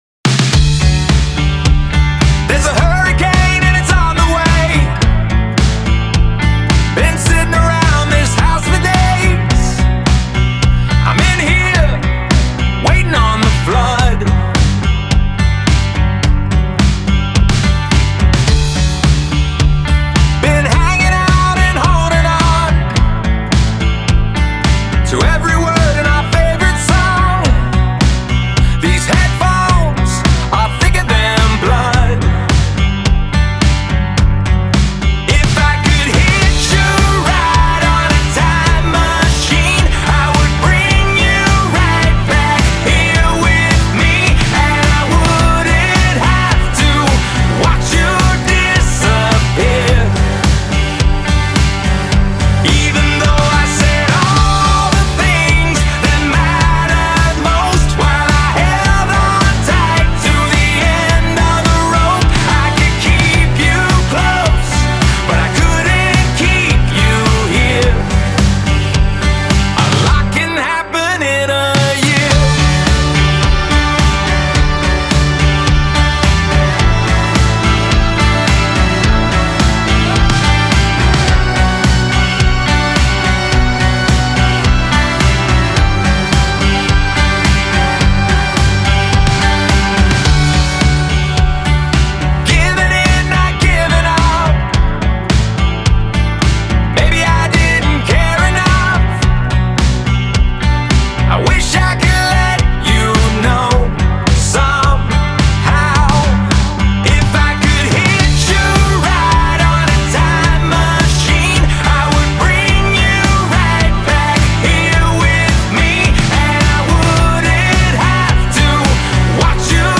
Трек размещён в разделе Зарубежная музыка / Рок.